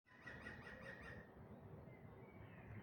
Unter anderem hörte ich einen Grünspecht.